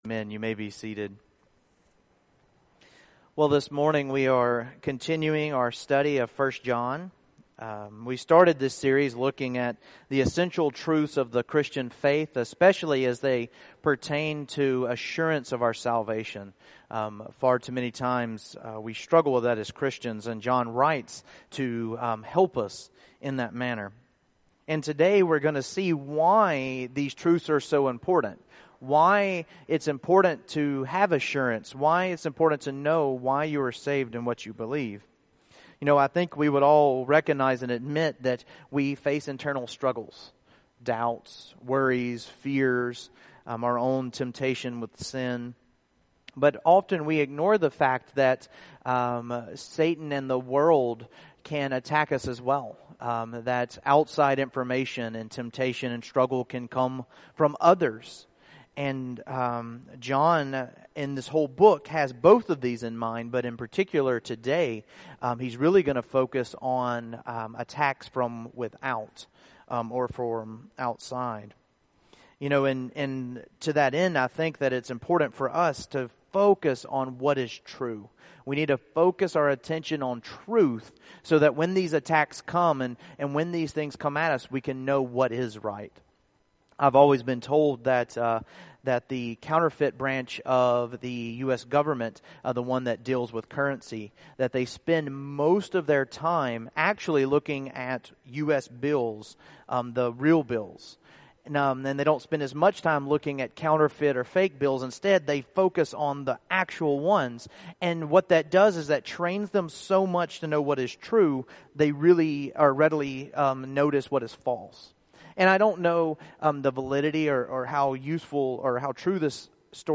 1 John 2:18-27 Service Type: Morning Worship I. Recognize the deceivers by their denial of Christ